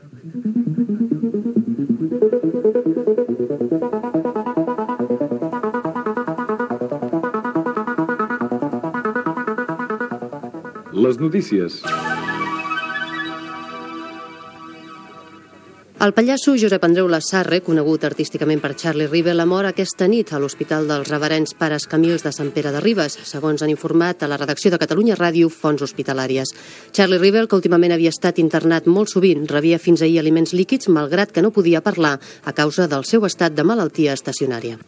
Informatiu
FM
Primera notícia que va facilitar Catalunya Ràdio al seu primer butlletí informatiu.